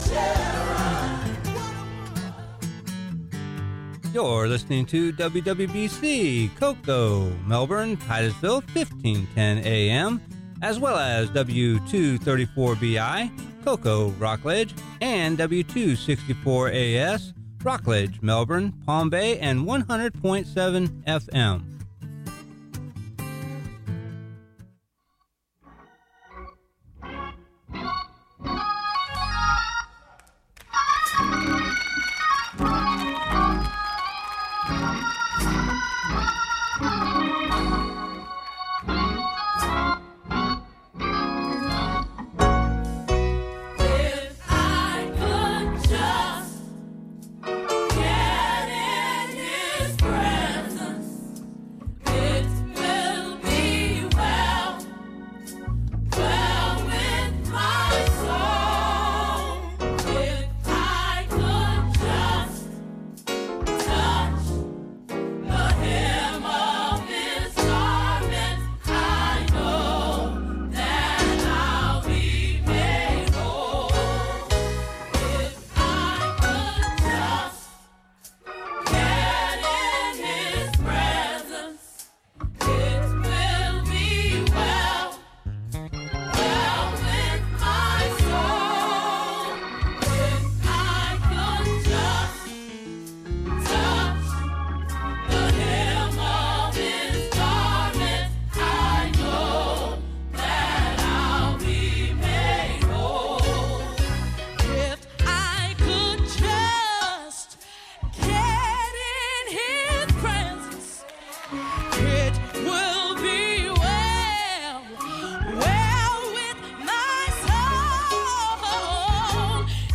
Sermon: Beware of the Dogs, Philippians Ch. 2 Part 1